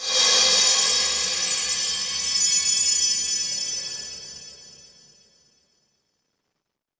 Cosmic Chimes short.wav